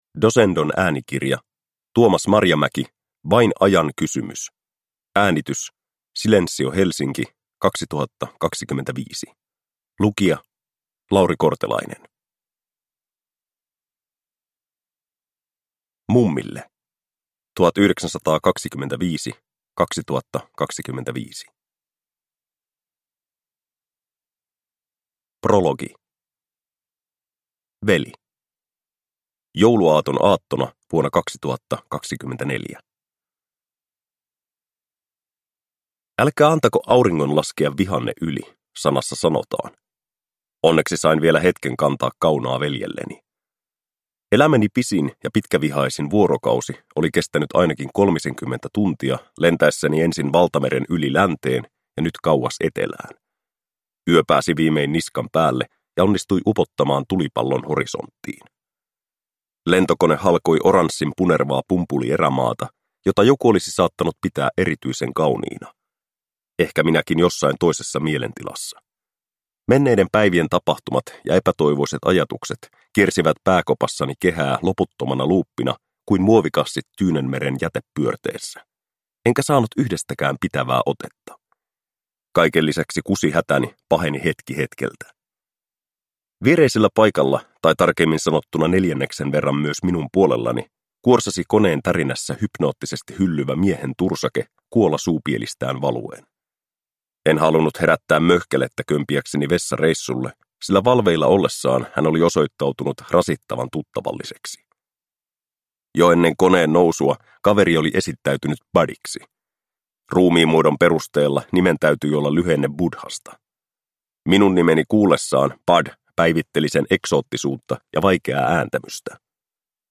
Vain ajan kysymys – Ljudbok